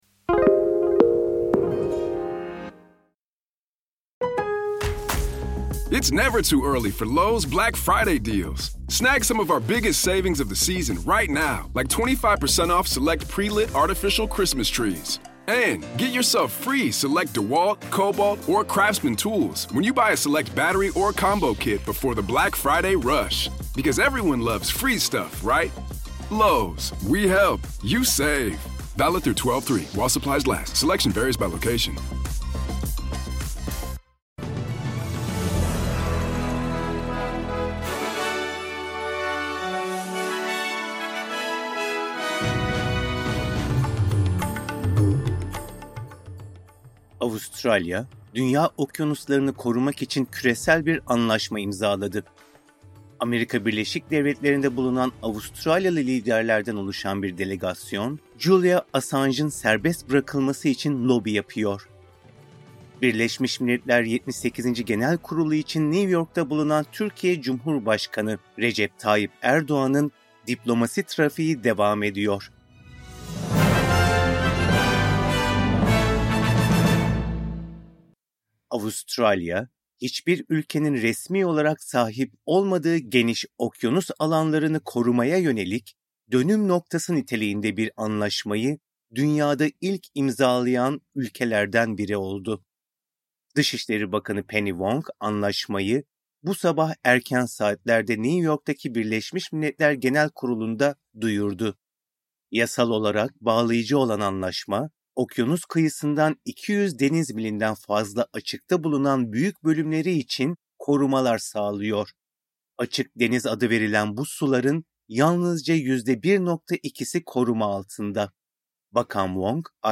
SBS Türkçe Haber Bülteni Source: SBS